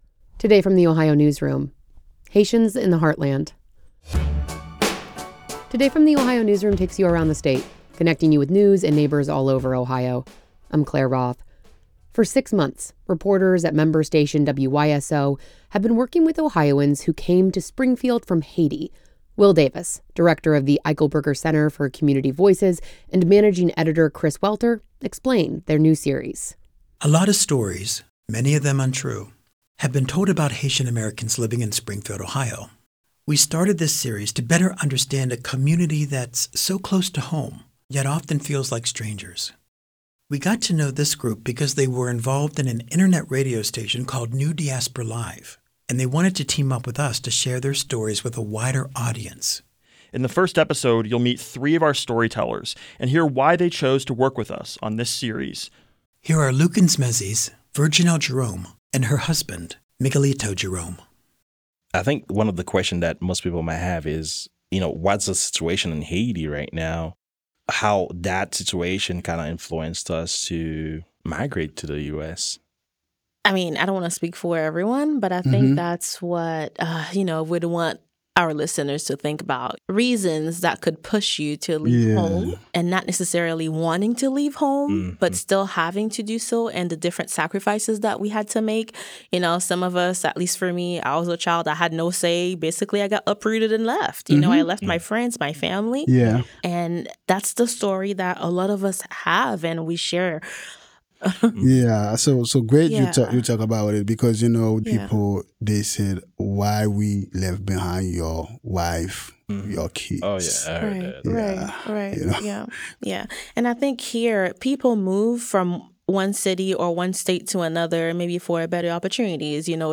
are radio professionals from Haiti who are now living and working in Springfield.
The following transcript is lightly edited for length and clarity.